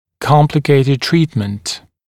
[‘kɔmplɪkeɪtɪd ‘triːtmənt][‘компликейтид ‘три:тмэнт]сложное лечение